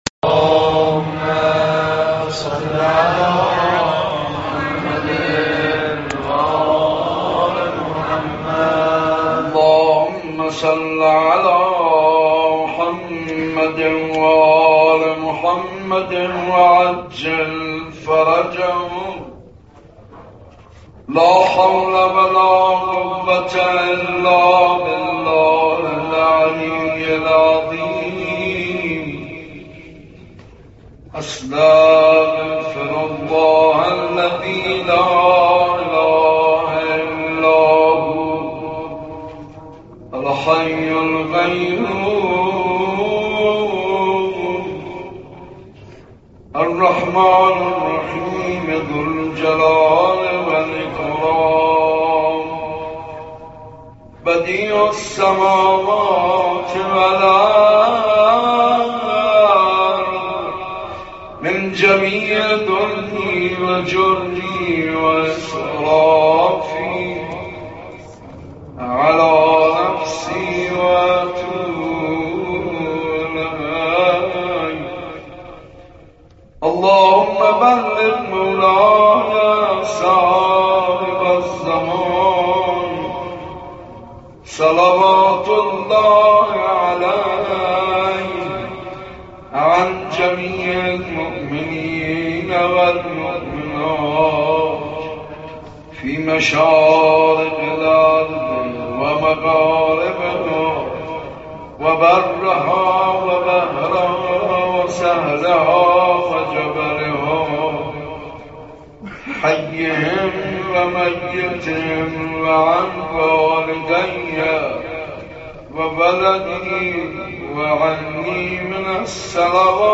صوت / مناجات با امام زمان(عج)